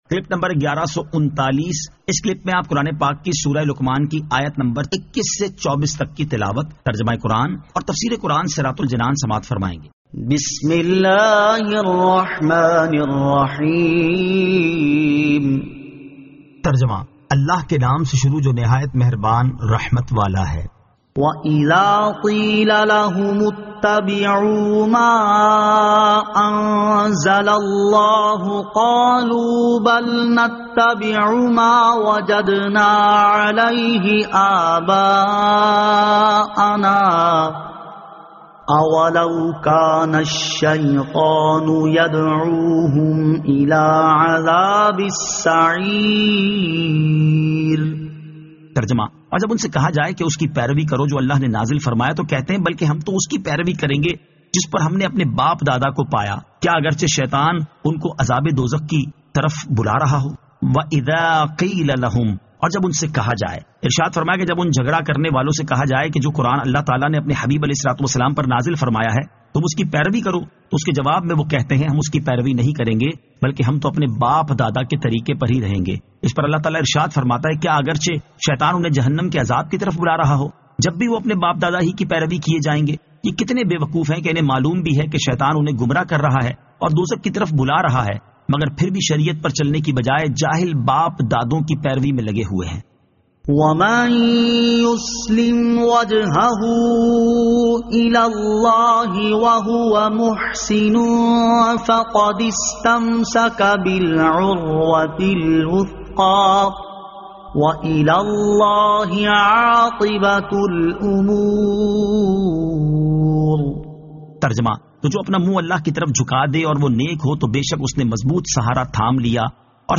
Surah Luqman 21 To 24 Tilawat , Tarjama , Tafseer